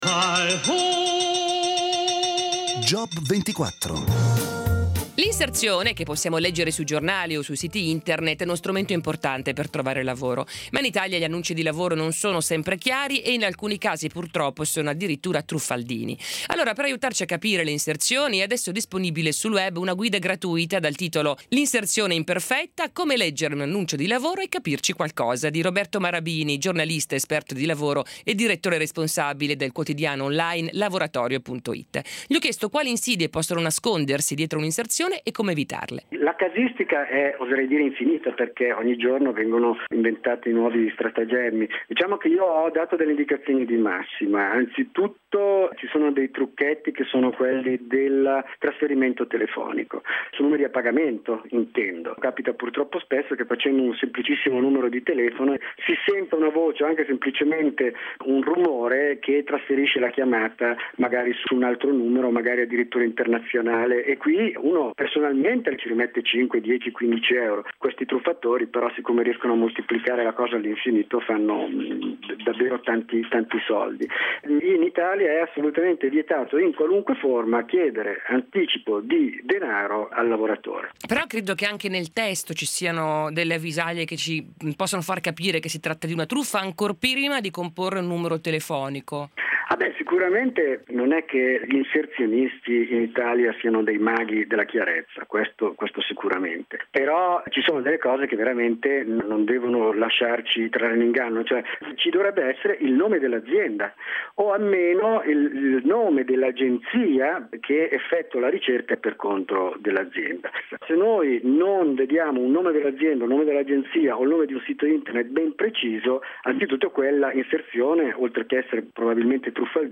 Che negli anni è stato favorevolmente recensito da giornalisti specializzati di radio, periodici e quotidiani (qui è possibile trovare l’audio dell’intervista di